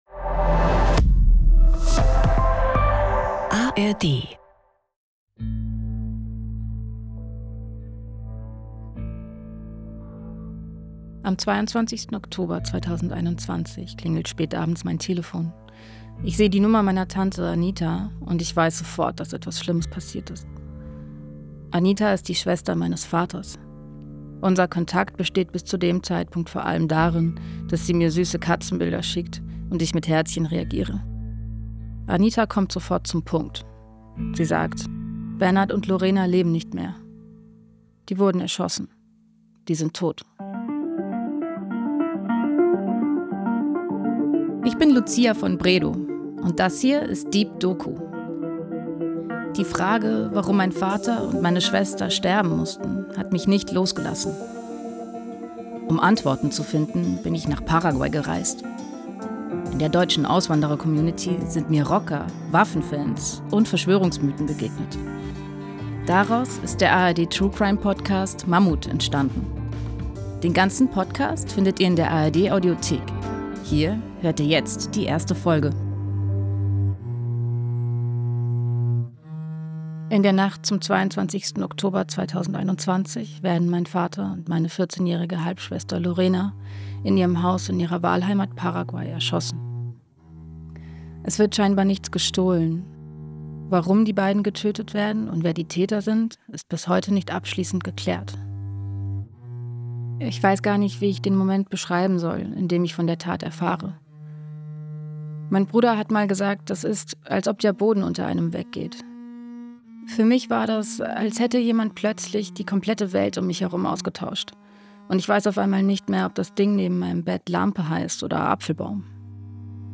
Deep Doku erzählt persönliche Geschichten und taucht alle zwei Wochen in eine andere Lebensrealität ein. Egal ob im Technoclub, der Notrufzentrale der Feuerwehr, auf einer Demo oder im Wohnzimmer – wir sind in unseren Audio-Dokus und Reportagen ganz nah dran.